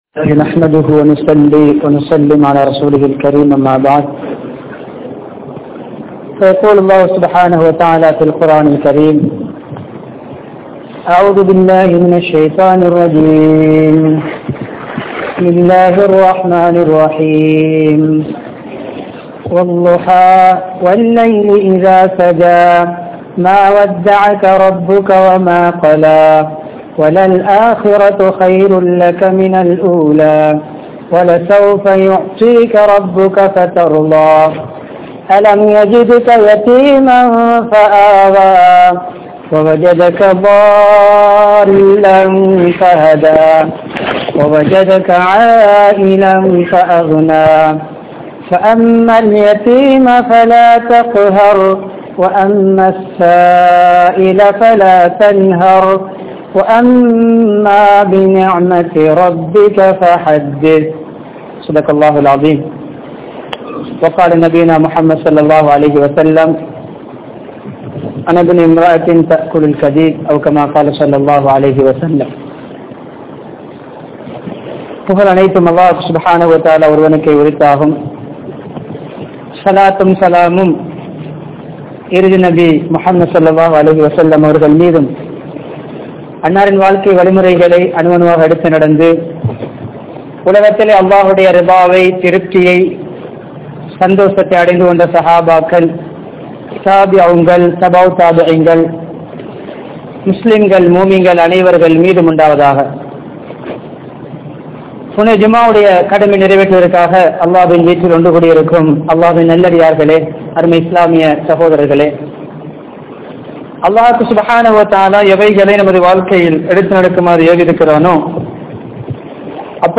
Don`t Forget Your Past (கடந்த காலத்தை மறந்து விடாதீர்கள்) | Audio Bayans | All Ceylon Muslim Youth Community | Addalaichenai
Ulahitiwela Jumua Masjidh